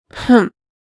sigh.ogg